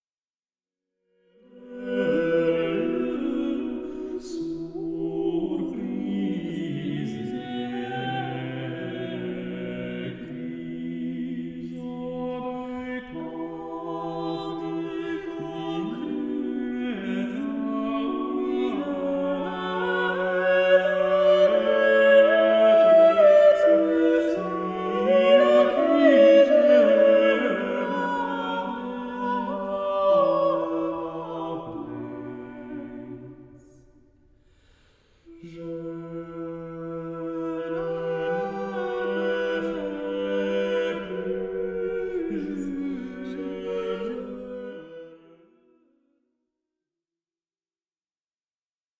Polyphonies courtoises
harpe, vièle, luth, rebec et clavicythérium